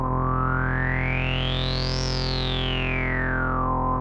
MOOG SWEEP B.wav